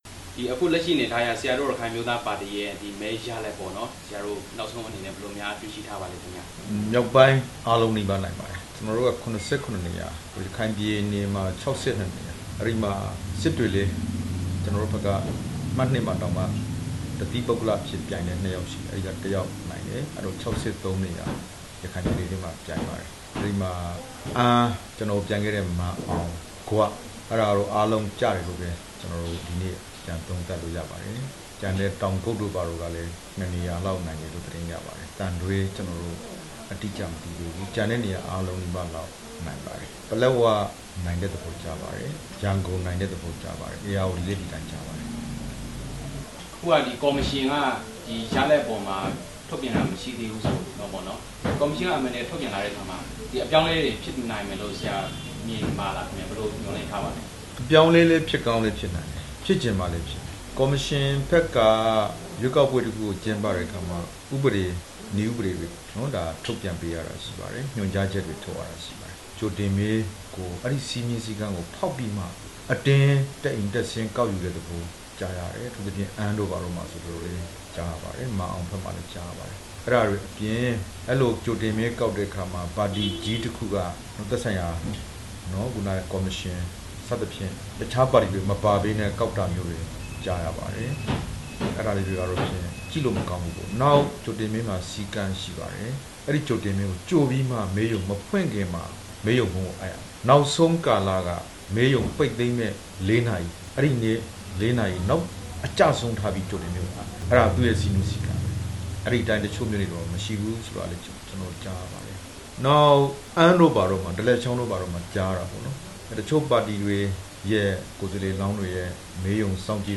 ရခိုင်အမျိုးသားပါတီဥက္ကဌ ဒေါက်တာအေးမောင်နဲ့ မေးမြန်းချက်